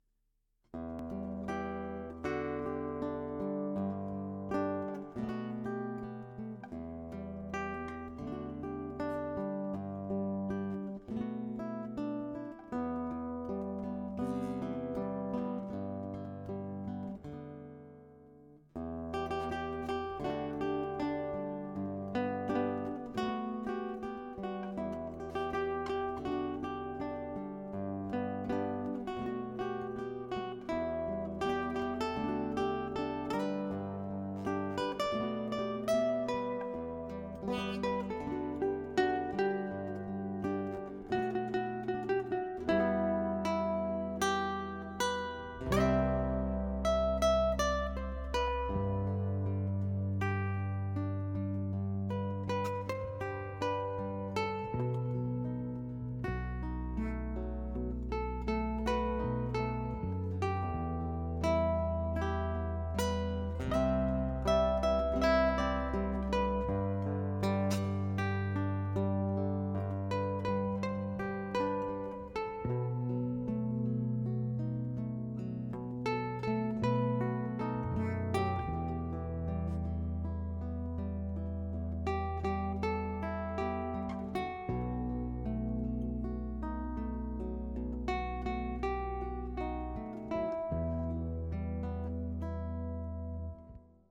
Что это: мелодия песни.
Подключил гитару проводом через DI к преду, потом в АЦП. Добавил по ходу бас через DI.
Это чисто тест аппаратуры, но на основе диктофонной демки.
Тут для записи попытался мелодию сыграть на гитаре, но т.к. до этого не играл её никогда, это типа почти сходу на слух.